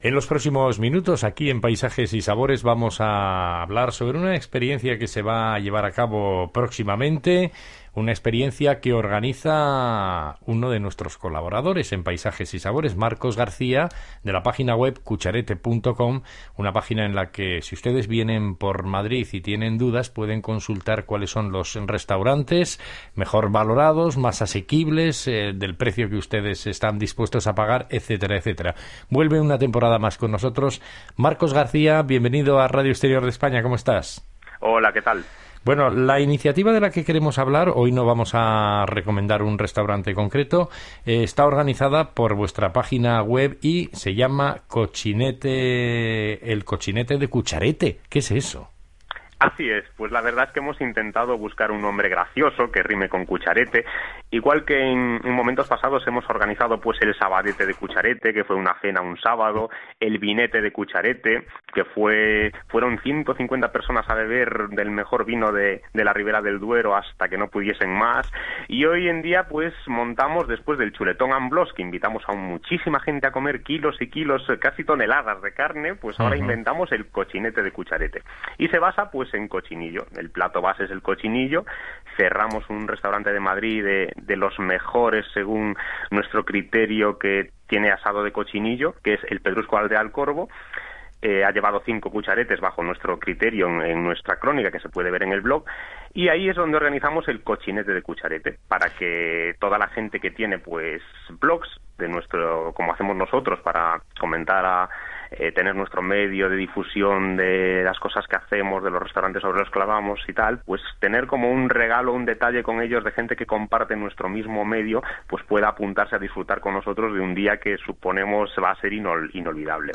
(En algunos navegadores el audio que viene a continuación se escucha acelerado)